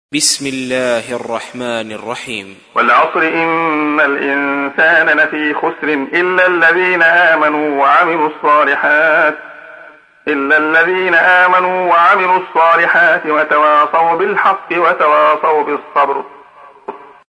تحميل : 103. سورة العصر / القارئ عبد الله خياط / القرآن الكريم / موقع يا حسين